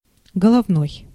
Ääntäminen
Translitterointi: golovnoi.